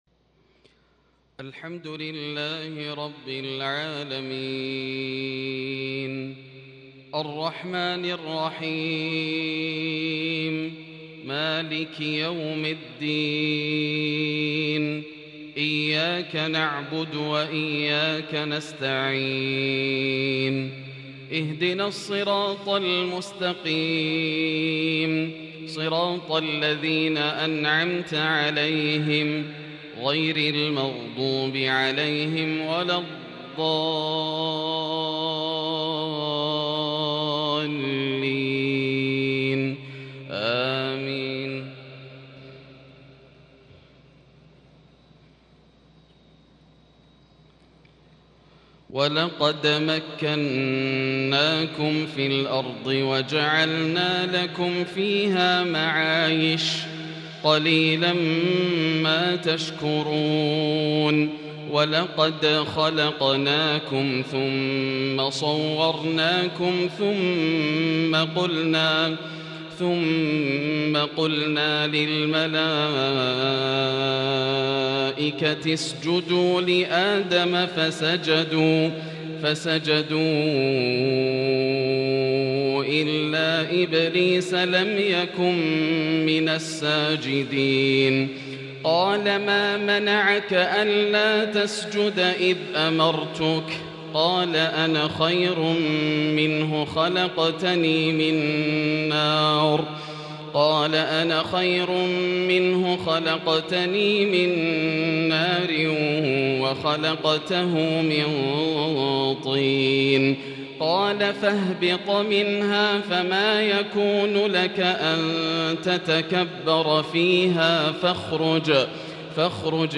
عشاء السبت 6-4-1442هـ من سورة الأعراف  Isha prayer from surah AlA'raf 21/11/2020 > 1442 🕋 > الفروض - تلاوات الحرمين